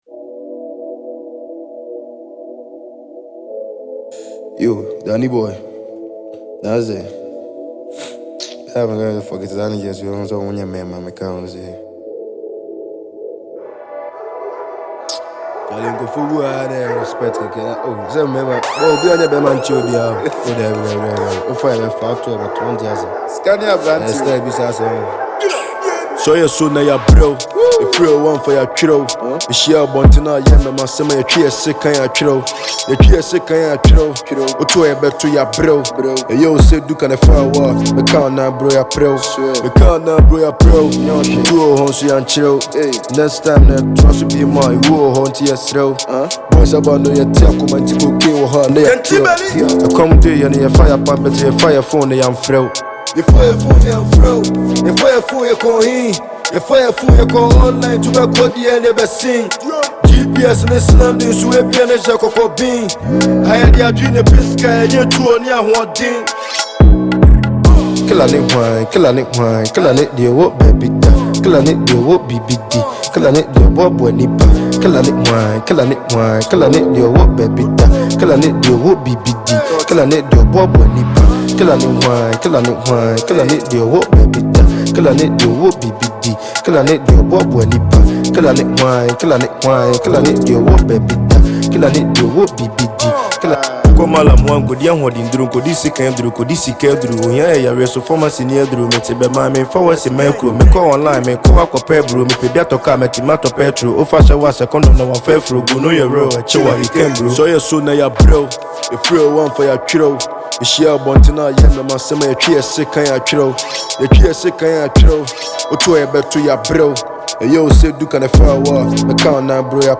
Ghanaian drill sensation
tough Asakaa anthem